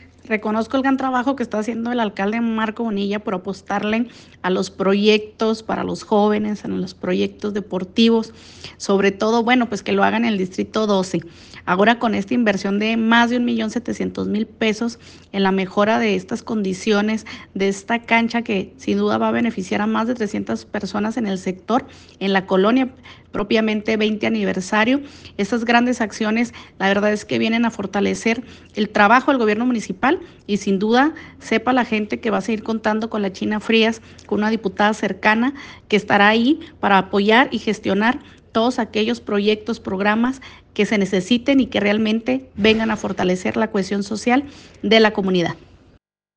MENSAJE DE NANCY FRÍAS | DIPUTADA LOCAL